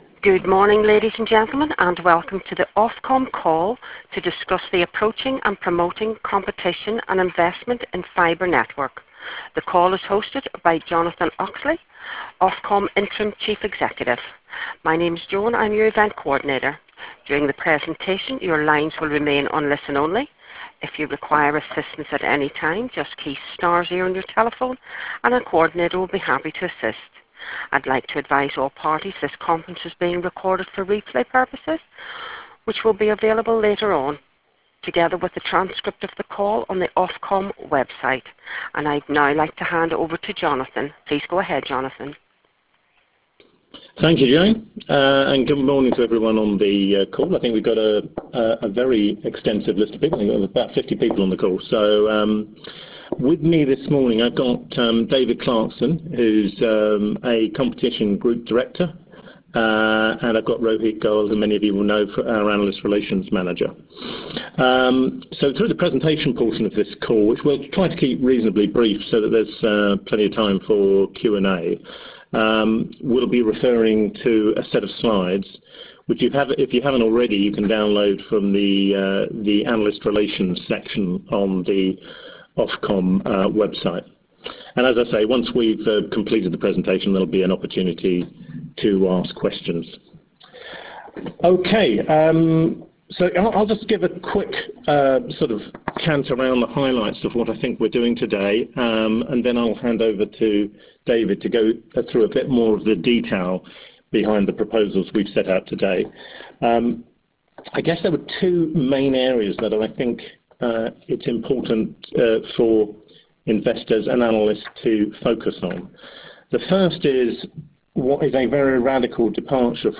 Access Review analyst briefing (conference call recording)
Ofcom held a conference call for analysts and investors to discuss our consultation Promoting competition and investment in fibre networks: Wholesale Fixed Telecoms Market Review 2021-2026.